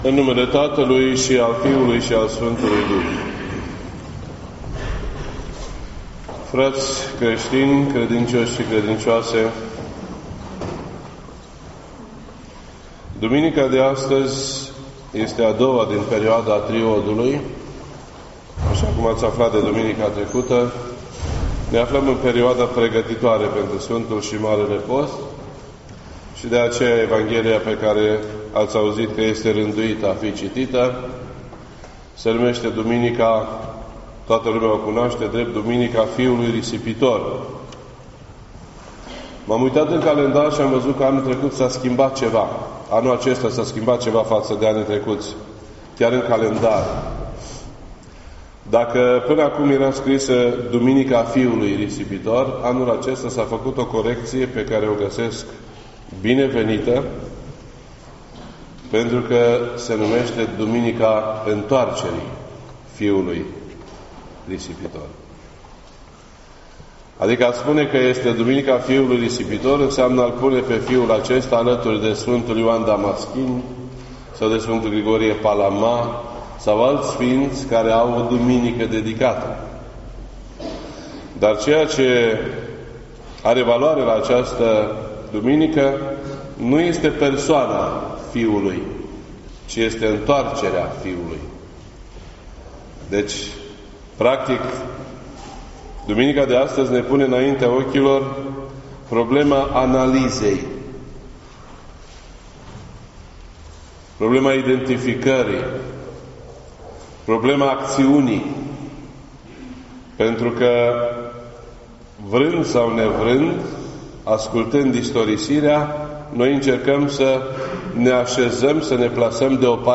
This entry was posted on Sunday, February 4th, 2018 at 1:44 PM and is filed under Predici ortodoxe in format audio.